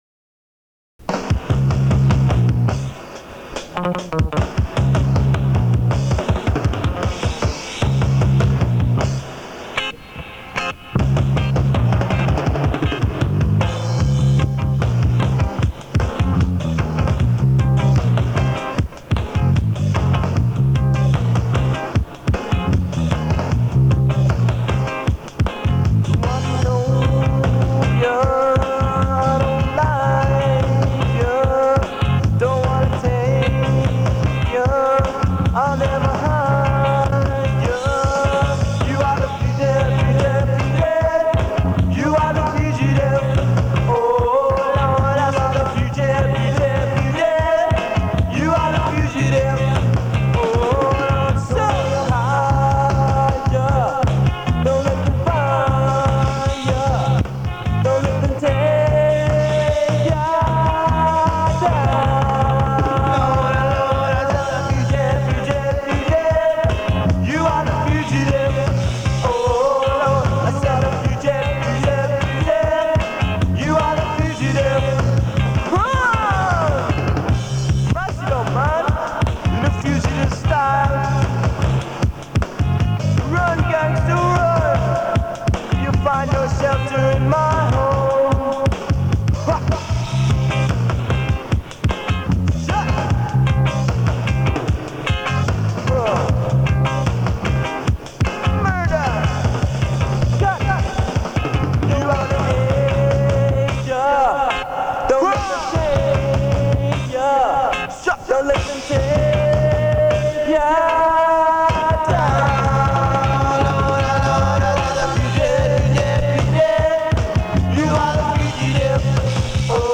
singing and some guitar
vocals
bass
Drums.